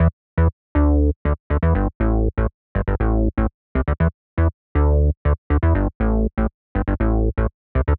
23 Bass PT1.wav